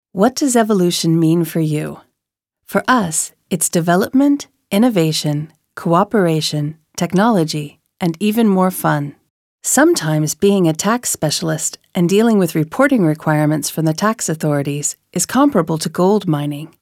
Ukázka hlasu: